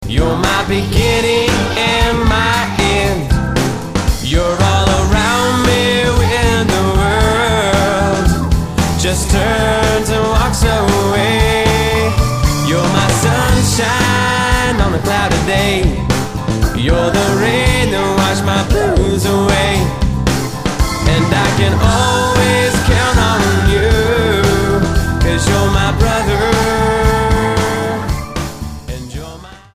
STYLE: Childrens
Certainly the musicianship is of a high standard